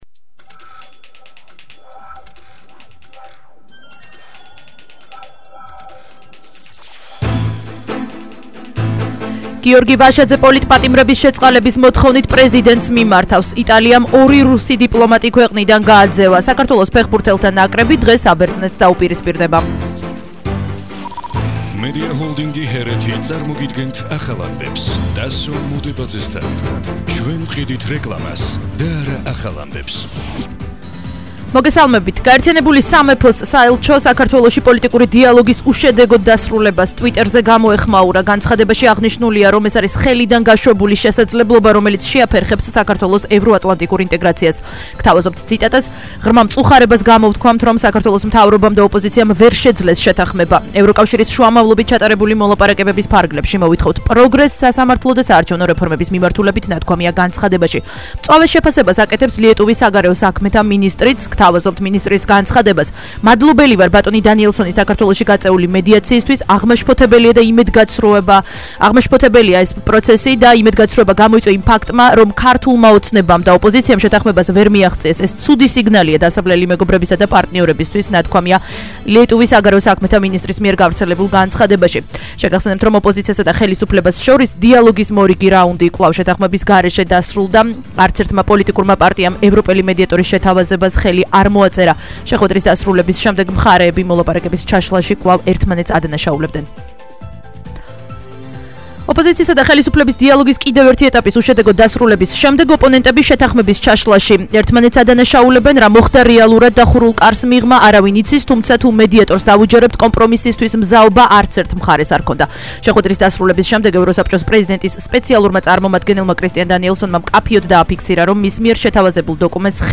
ახალი ამბები 16:00 საათზე –31/03/21 - HeretiFM